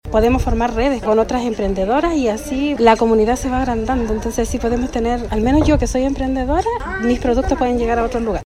Hasta Pichasca, en la comuna de Río Hurtado, llegaron mujeres de Punitaqui, Combarbalá, Monte Patria y Ovalle, quienes formaron parte de un valioso encuentro provincial, impulsado desde el Servicio Nacional de la Mujer y la Equidad de Género (SernamEG) junto a los municipios ejecutores de la provincia.